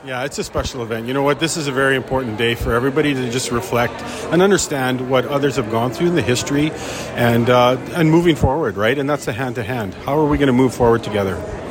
EPS Deputy Chief Darren Derko spoke with CFWE and says it’s a special event to be a part of, and creating ways of being “Hand in Hand” towards a path of working together.